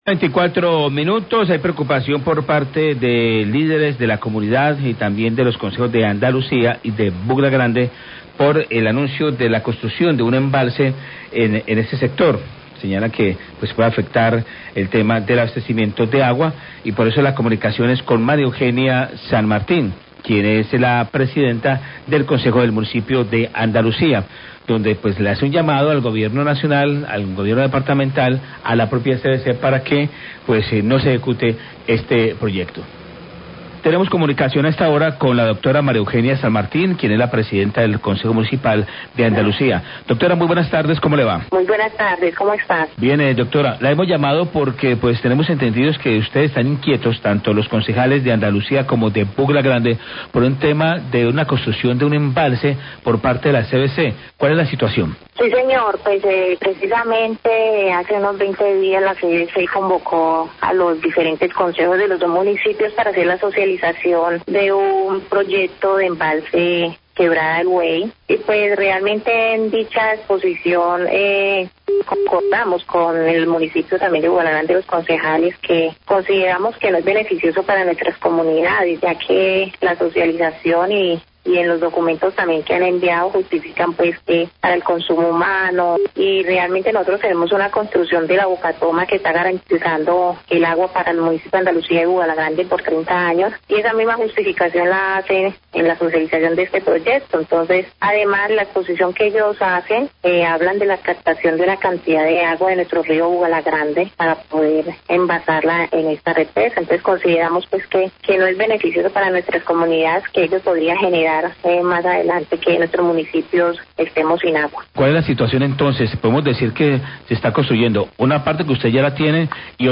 Radio
El director de la Corporación Autónoma, Ruben Darío Materón, respondió a las inquietudes.